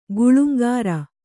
♪ guḷumgāra